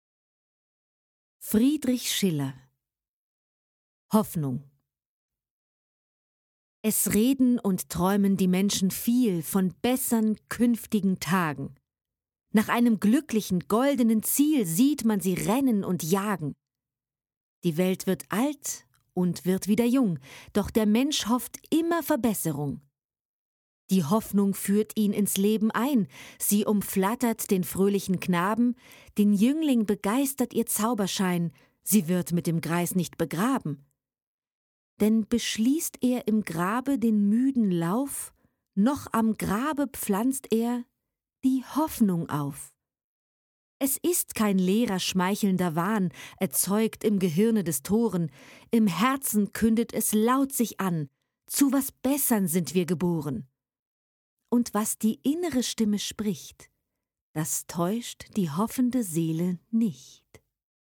Lyrik, Schullektüre
gesprochen